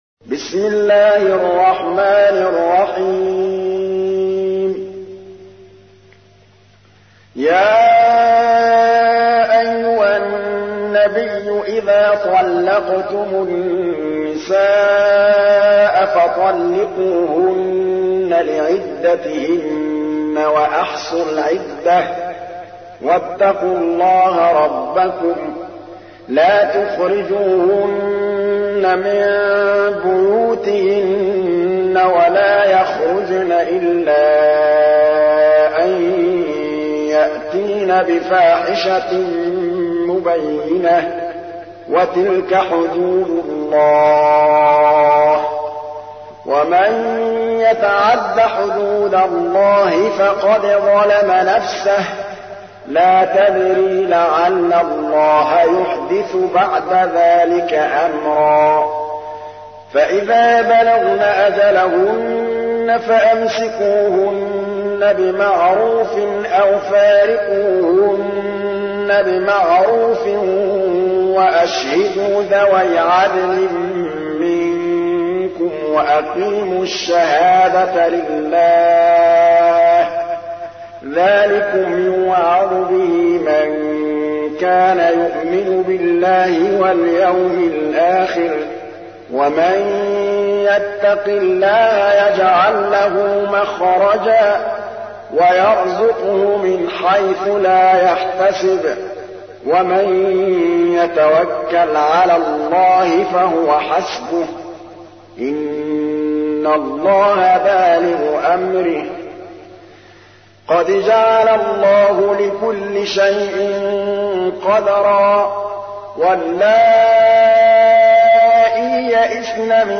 تحميل : 65. سورة الطلاق / القارئ محمود الطبلاوي / القرآن الكريم / موقع يا حسين